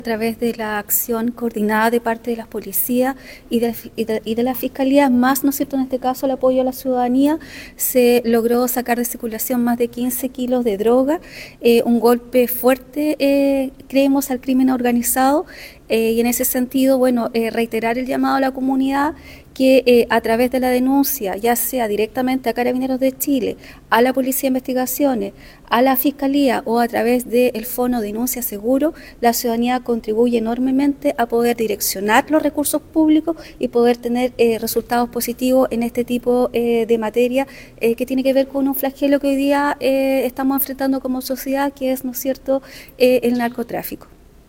Por último, la Delegada Presidencial Provincial Claudia Pailalef, destacó la importancia de estos operativos, que entregan valiosos aportes en la lucha contra el narcotráfico y el crimen organizado.